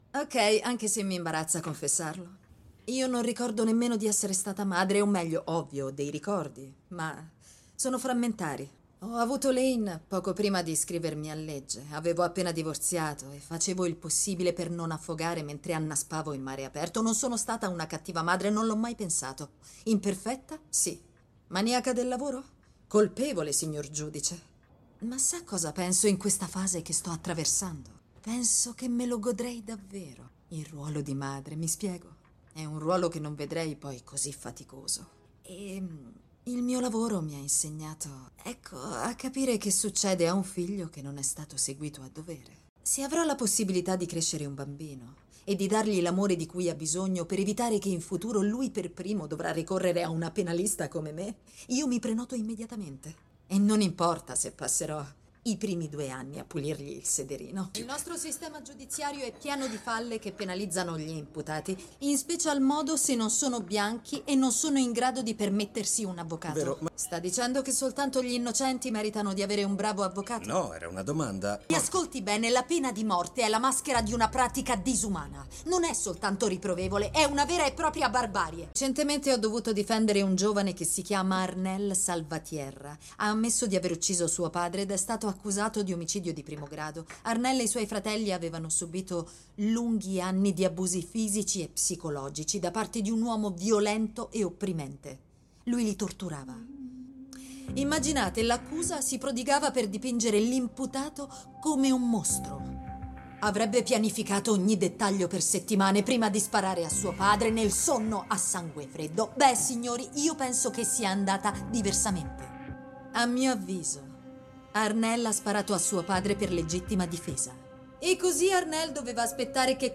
vocefdb.mp3